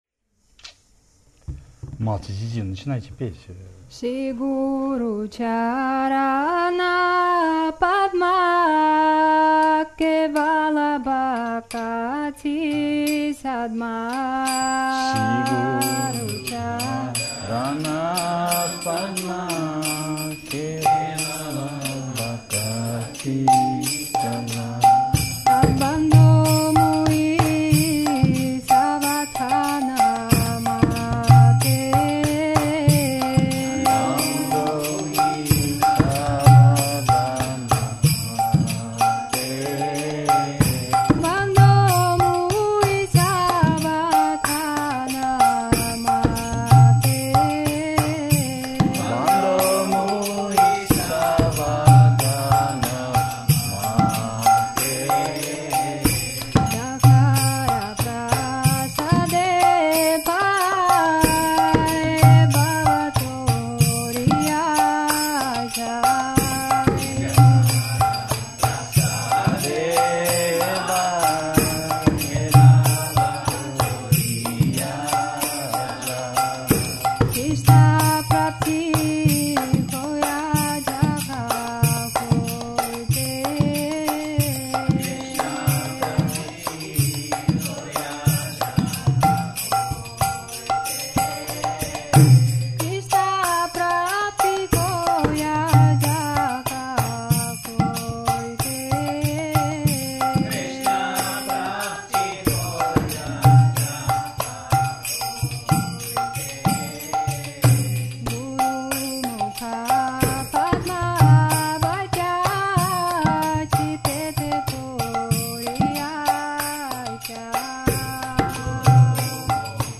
Place: Gupta Govardhan Chiang Mai
Kirttan , Harinam-sankirttan